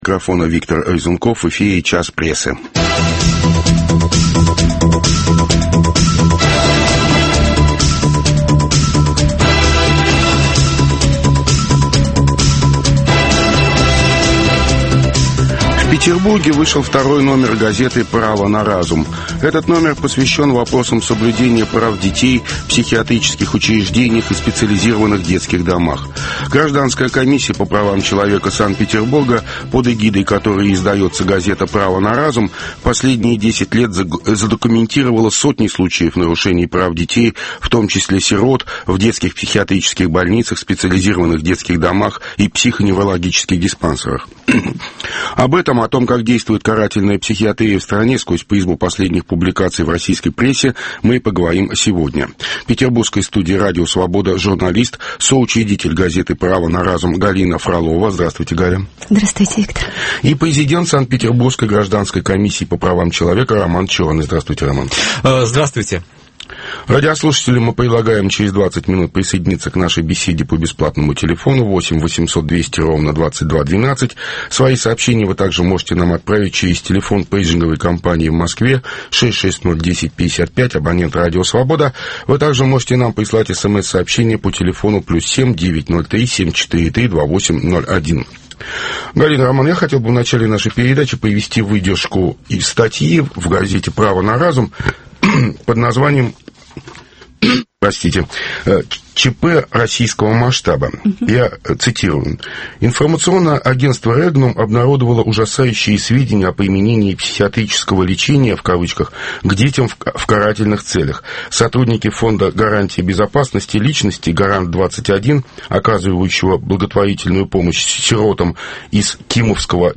Карательная психиатрия и дети... К выходу 2-го номера газеты "Право на разум". Обсуждают